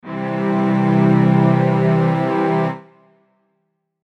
Why would this be restful and sonorous:
major